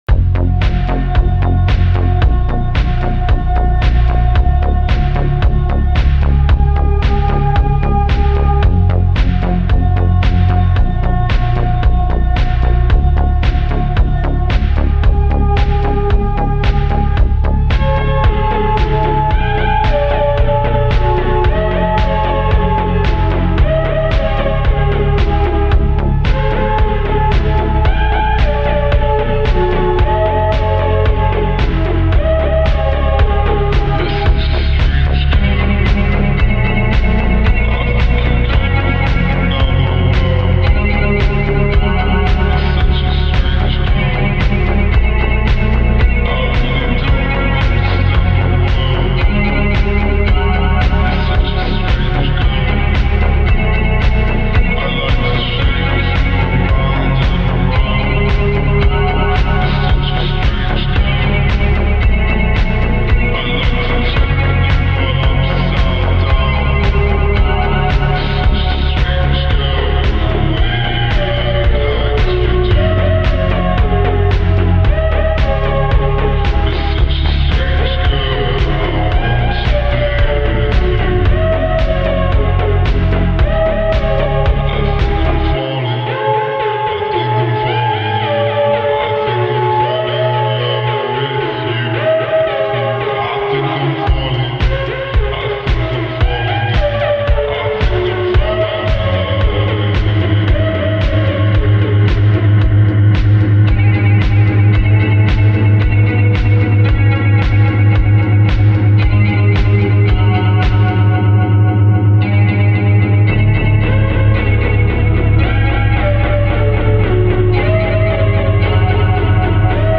فانک